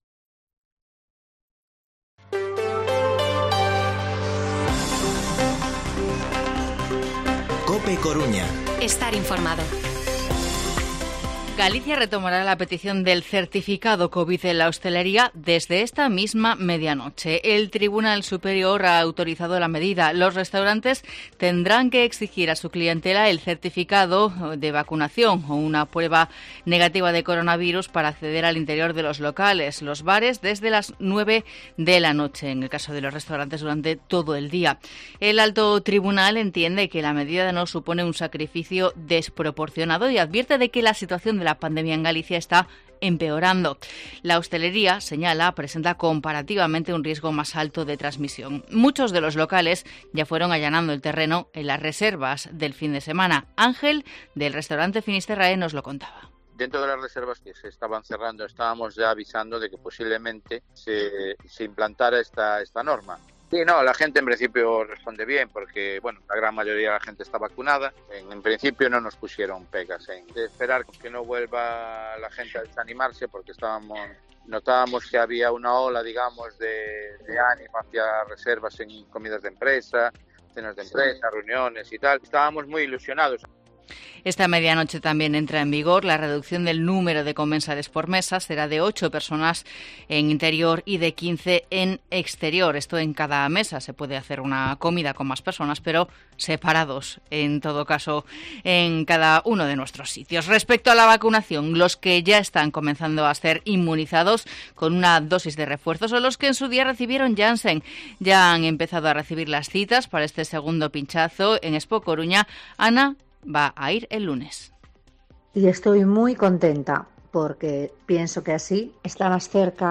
Coruña